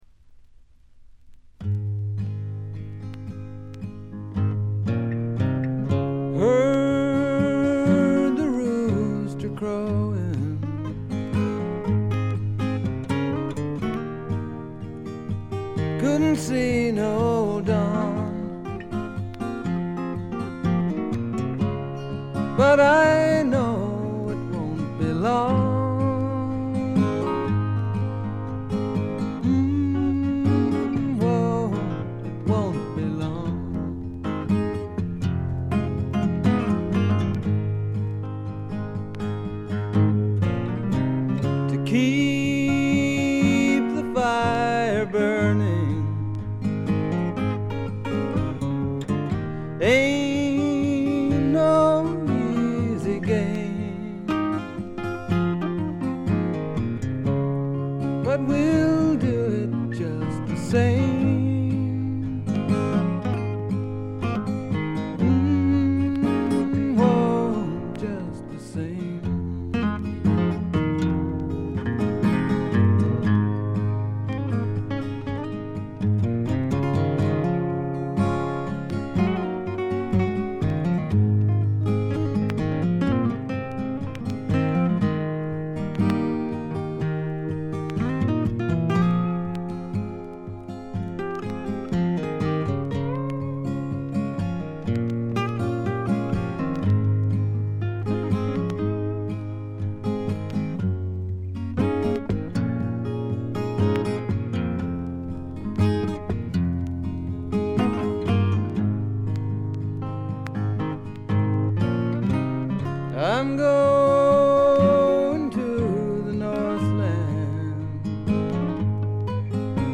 ところどころでチリプチ。
静謐で内省的なホンモノの歌が聴ける名作です。
ほとんどギターの弾き語りで、ギターインストのアコギの腕前も素晴らしいです。
試聴曲は現品からの取り込み音源です。